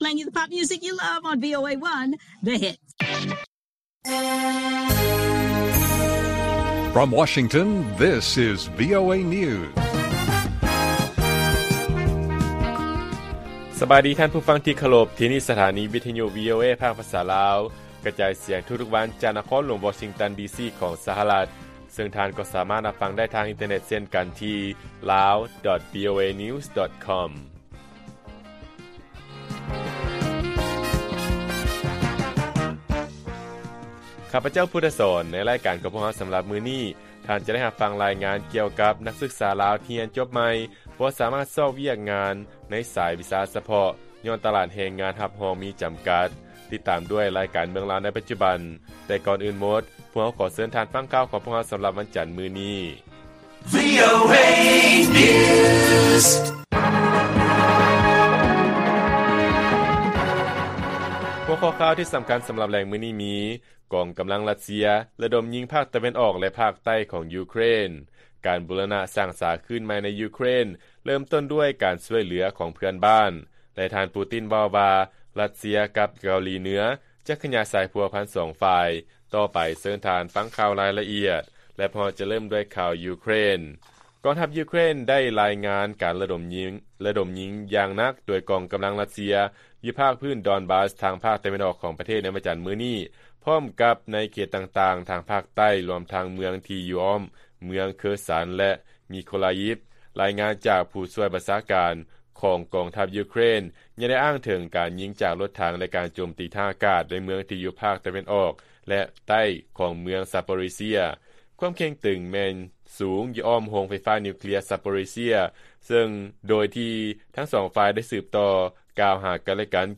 ລາຍການກະຈາຍສຽງຂອງວີໂອເອ ລາວ: ກອງກຳລັງ ຣັດເຊຍ ລະດົມຍິງ ໃສ່ພາກຕາເວັນອອກ ແລະ ພາກໃຕ້ຂອງ ຢູເຄຣນ